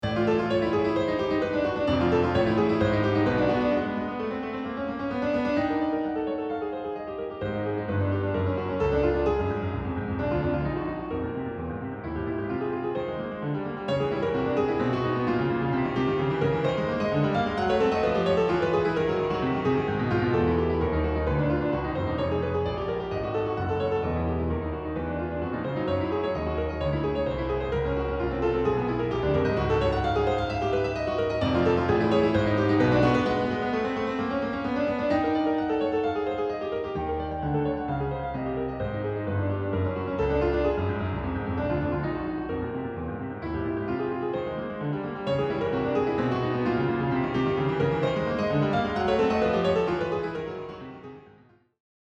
the chromatic